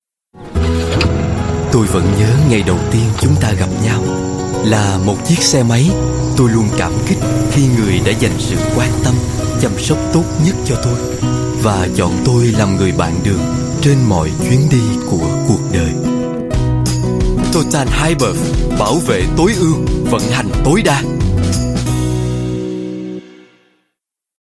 Viet South VIS